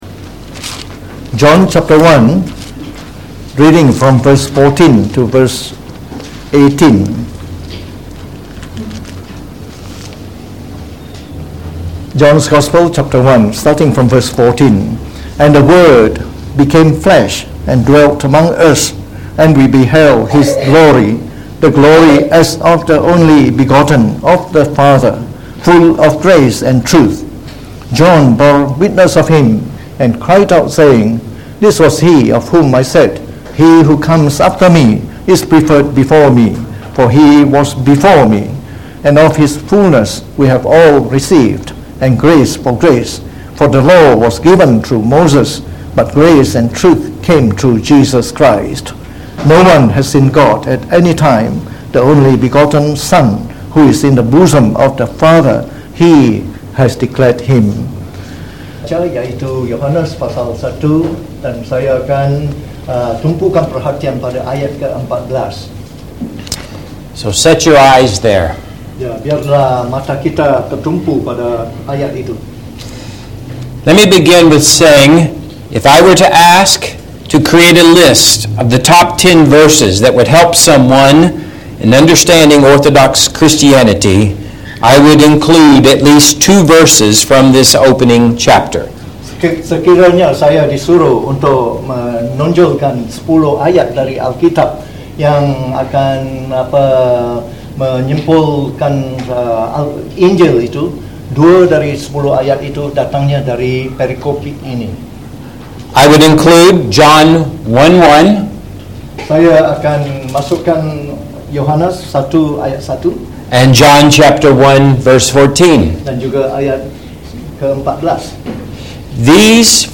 Preached on the 1st of Sept 2019.